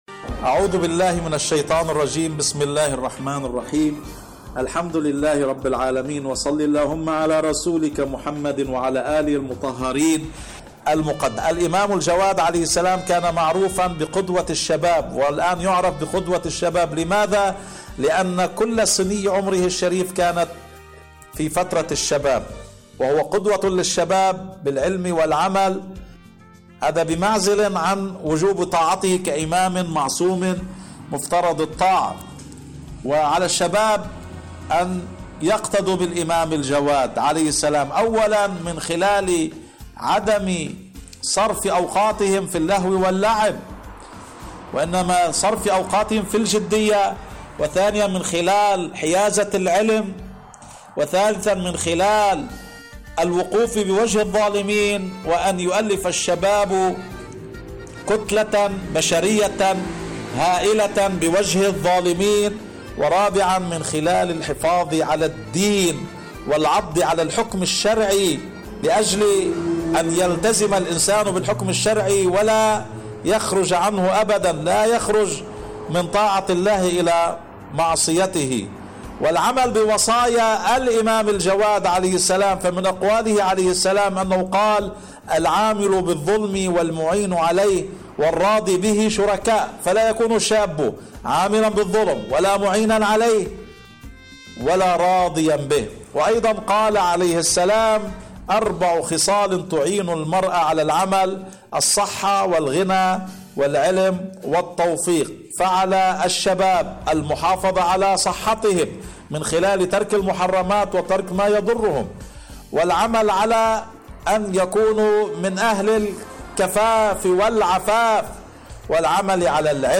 إذاعة طهران- دنيا الشباب: مقابلة إذاعية